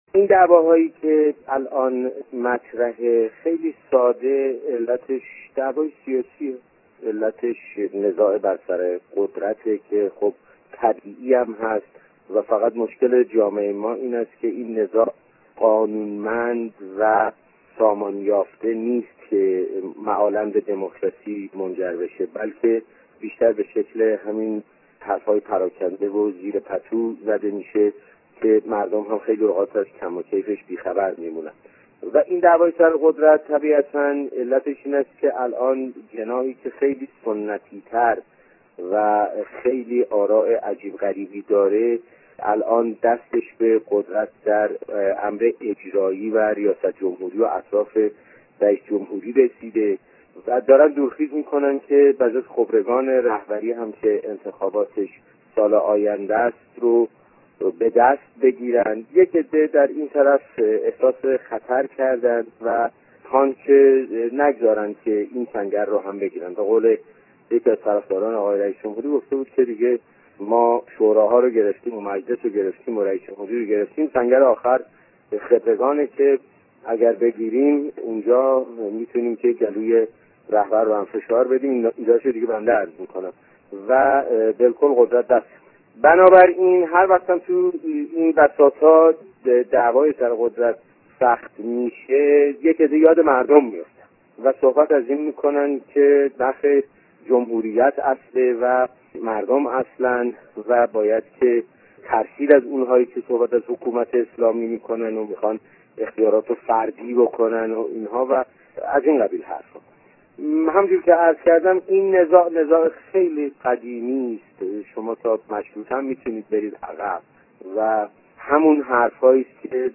چهارشنبه ۲۸ دیماه ۱۳۸۴ - ۳:۴۱ صبح | مصاحبه ها